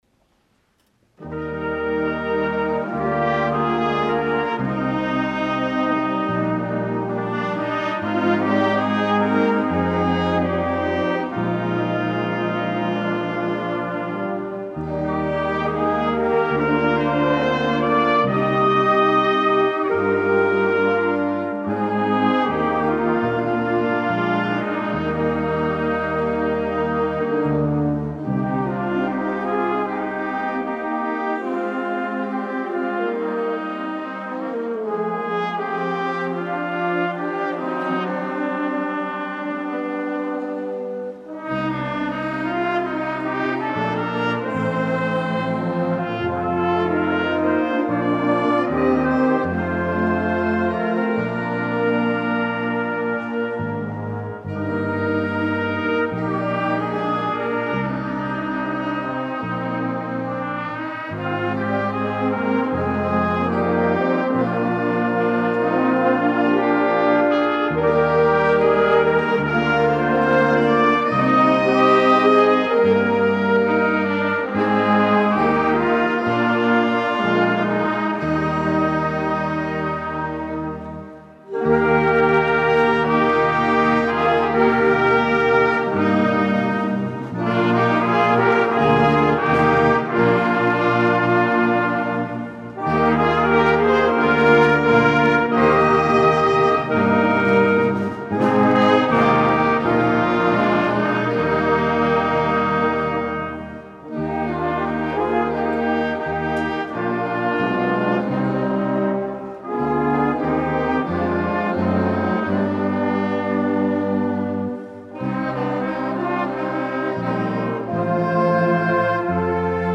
Konzert 2008